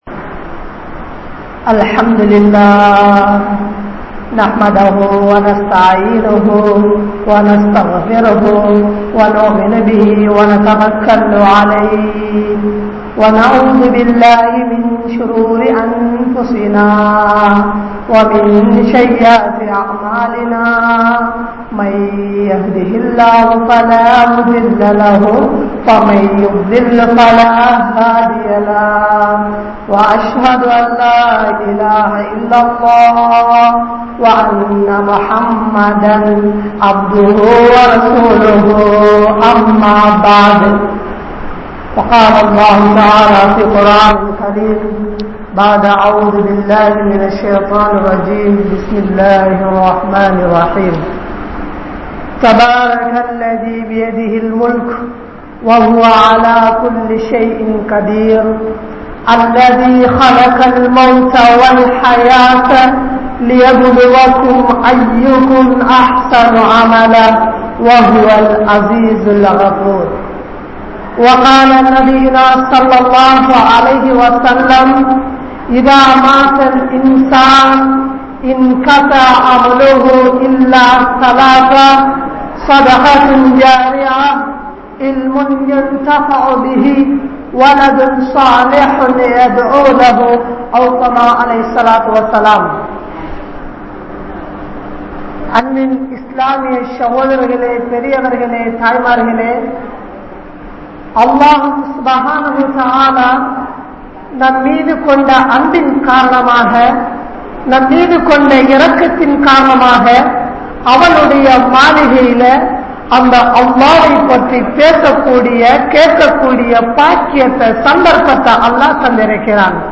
Sakraath & Maranam | Audio Bayans | All Ceylon Muslim Youth Community | Addalaichenai